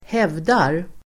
Uttal: [²h'ev:dar]